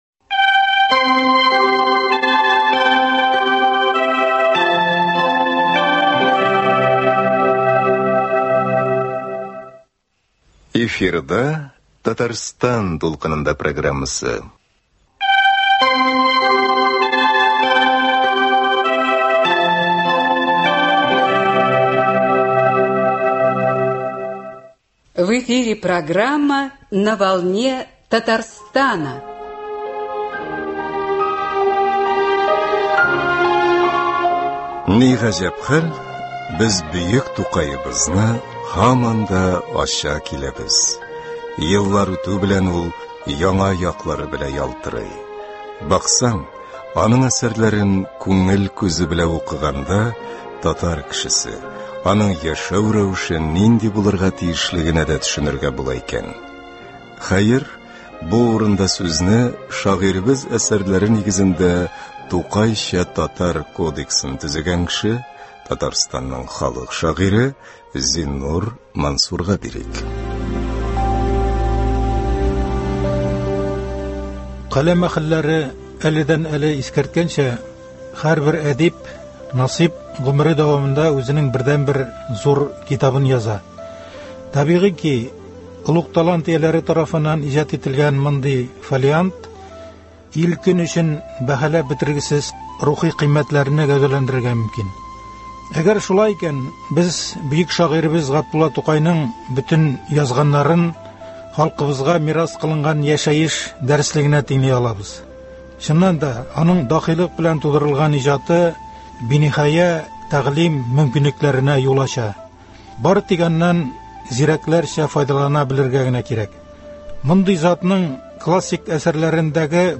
“Татарстан дулкынында” программасының бу чыгарылышында Сез Шагыйрь Зиннур Мансурның “Тукай белән әңгәмәләр” дигән китабы буенча эшләнгән радиокомпозициянең 4 нче өлешен тыңлый аласыз.